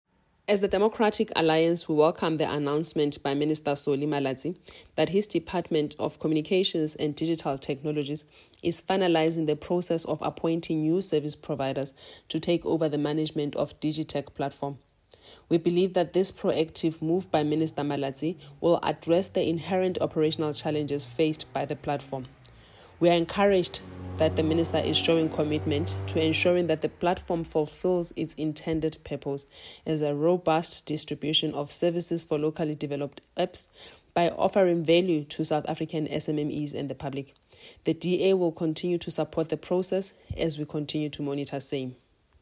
soundbite by Tsholofelo Bodlani MP.